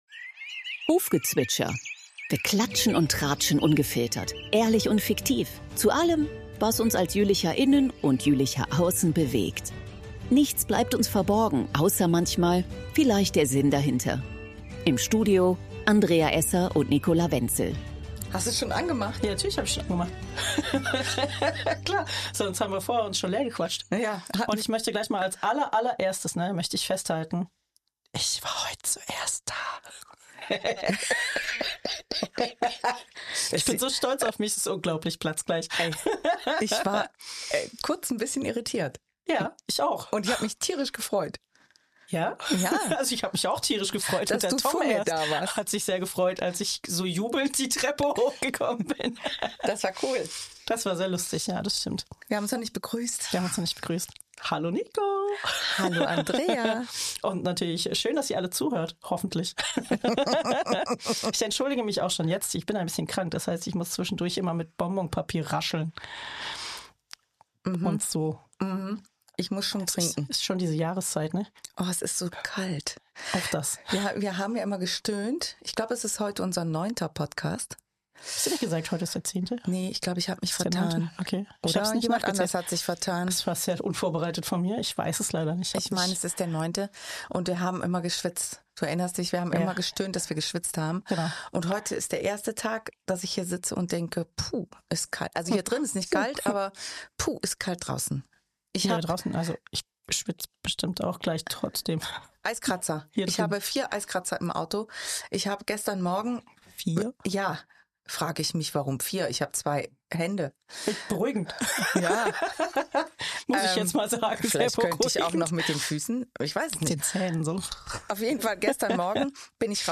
Fragen über Fragen, die zum 1. Advent besprochen werden – trotz Husten und allgemeiner Verwirrtheit.